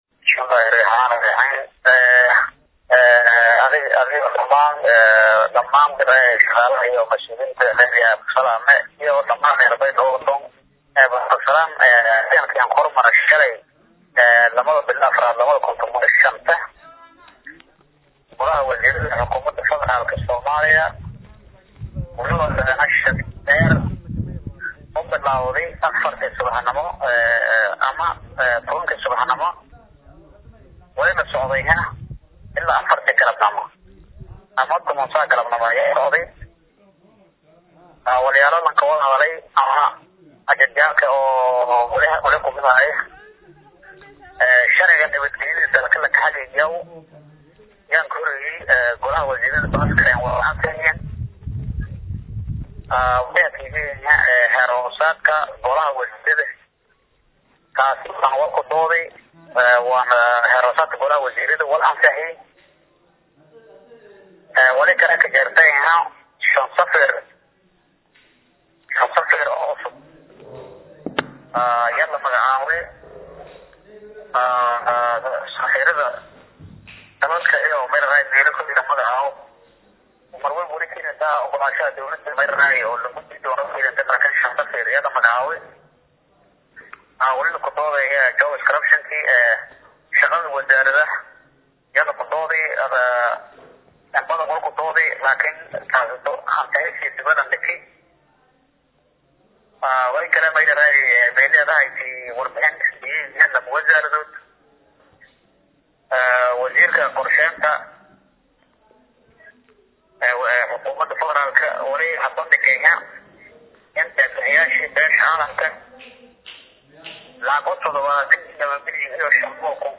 Muqdisho(INO)-Maxamed Isaaq Fanax Wasiiru Dowlaha wasaarada duulista Gaadiidka iyo Hawada ee dowlada soomaaliya ayaa wareysi uu siiyey Warbaahinta KGSoomaaliya ayaa wuxuu ku sheegey in Beesha Digil iyo Mirifle intii dowlada ay kujiraan xildhibaan ama wasiir ay go’aan sadeen in la taageero dowlada KGSoomaaliya oo bilwalibana lasiiyo Aduun gaareya 200.000USD.